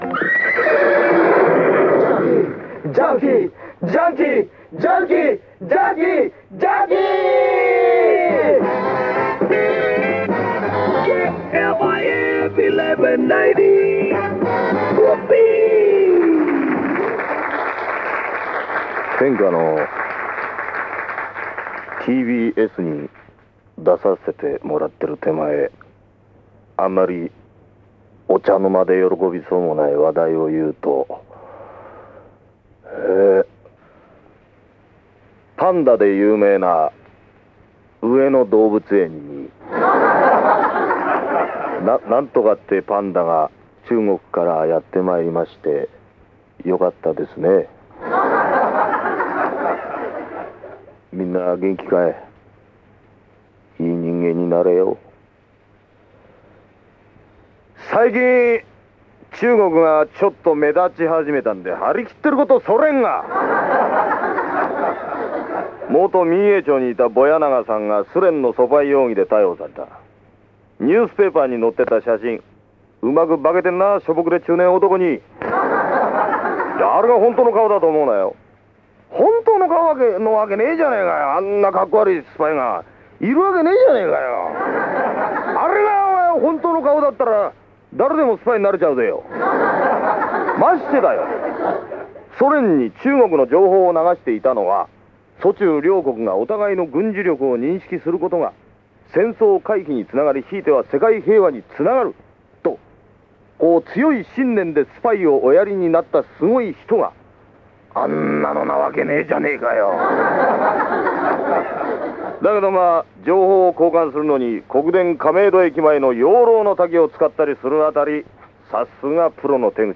TBSラジオエアチェック